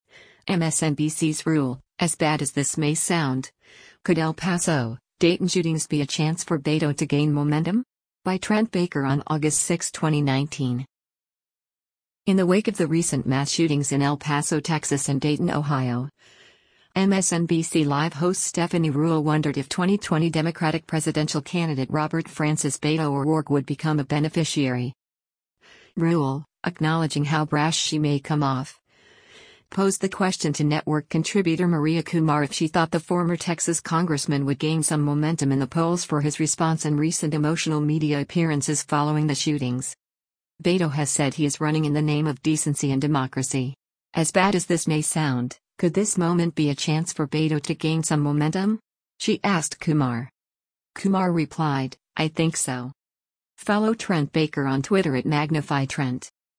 In the wake of the recent mass shootings in El Paso, TX and Dayton, OH, “MSNBC Live” host Stephanie Ruhle wondered if 2020 Democratic presidential candidate Robert Francis “Beto” O’Rourke would become a beneficiary.
Ruhle, acknowledging how brash she may come off, posed the question to network contributor Maria Kumar if she thought the former Texas congressman would gain some momentum in the polls for his response and recent emotional media appearances following the shootings.